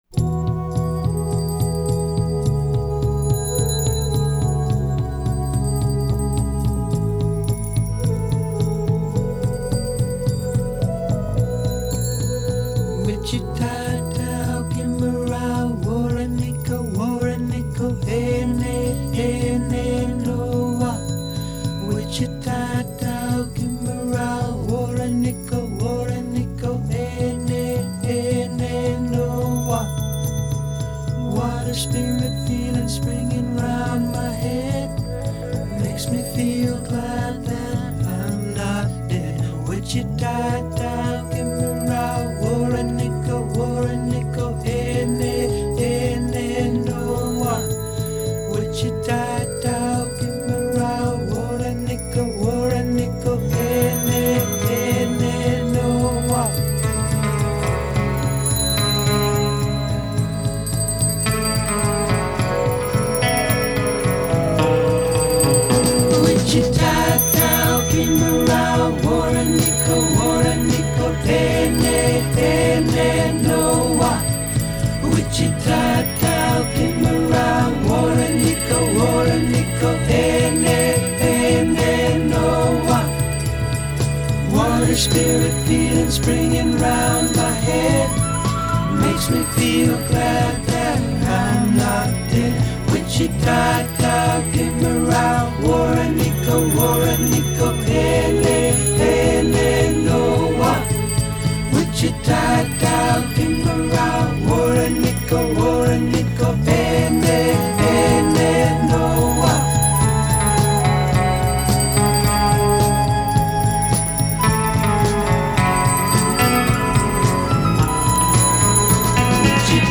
mysterious, psychedelic element
The delicious guitar solo